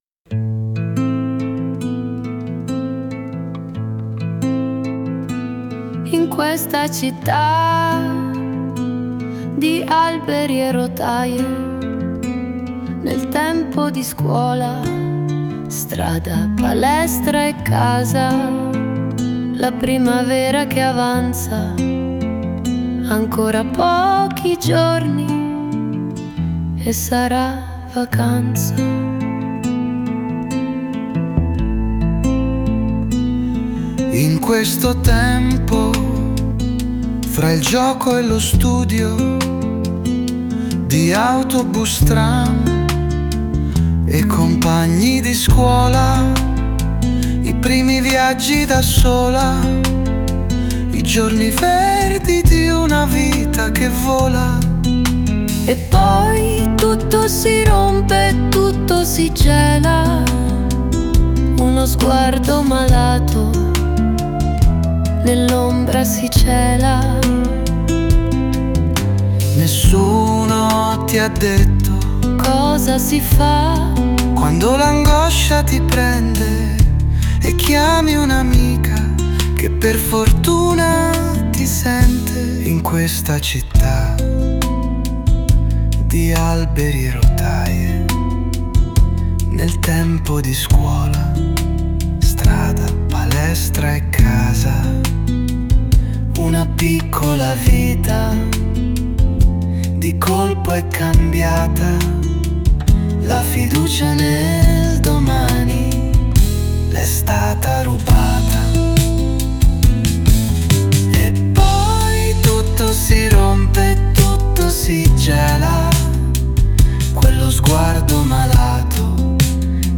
Il testo è interamente mio mentre melodia e canto sono stati creati con l'intelligenza artificiale, per l'esattezza sul sito Suno, a cui sto imparando a dare indicazioni sempre più precise sul risultato che voglio ottenere.